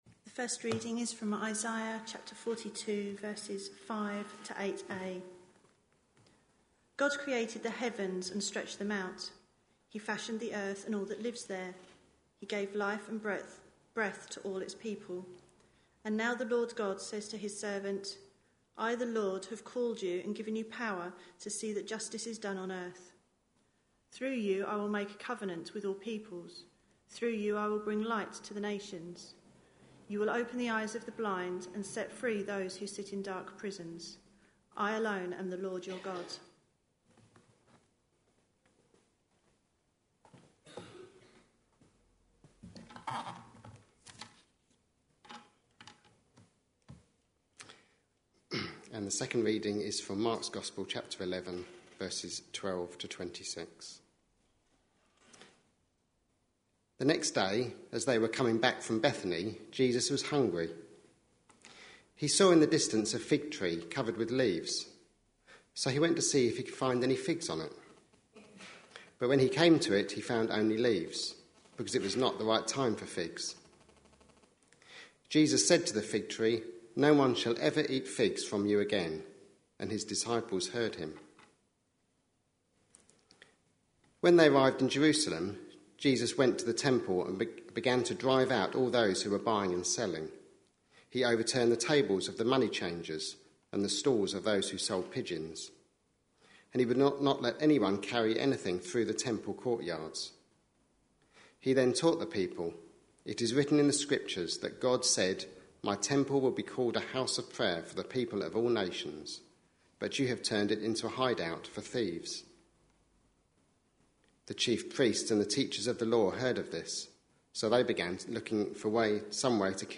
A sermon preached on 13th April, 2014, as part of our Shaken, Not Stirred series.